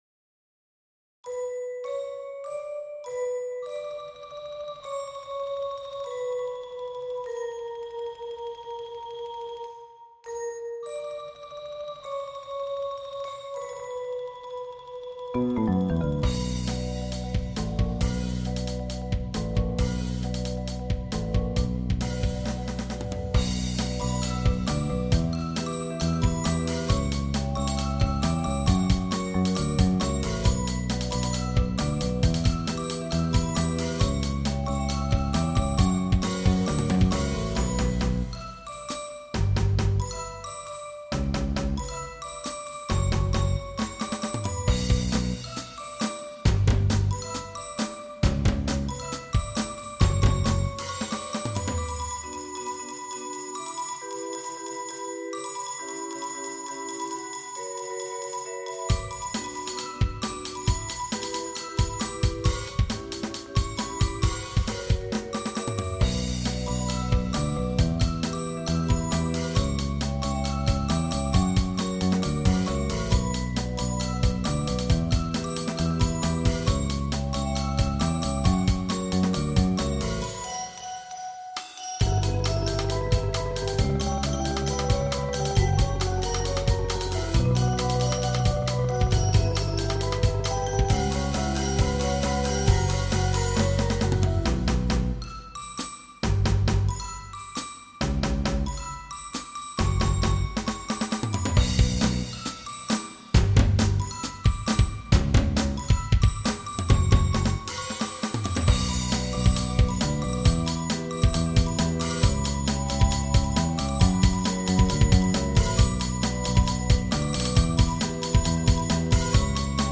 Mallet-Steelband Muziek